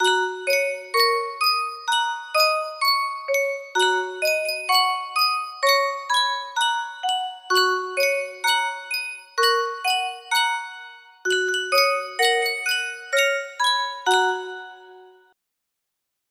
Sankyo Music Box - Shortnin' Bread VS music box melody
Full range 60